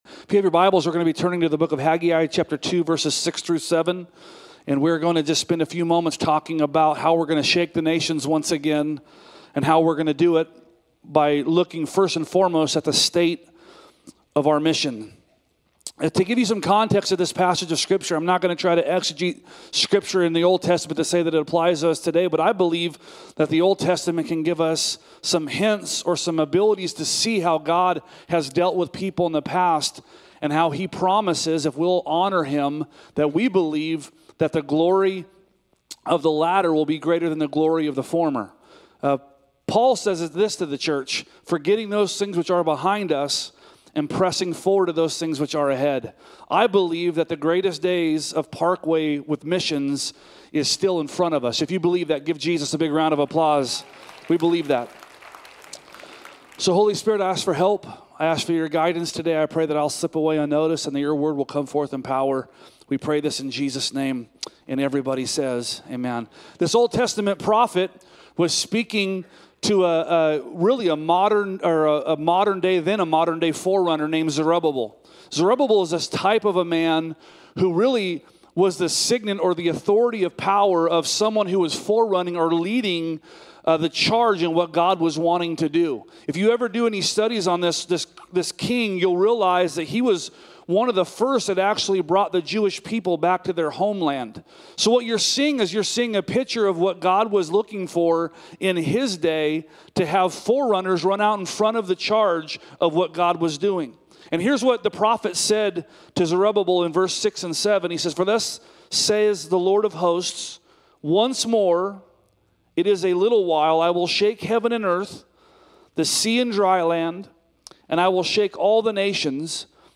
A message from the series "Shake the Nations."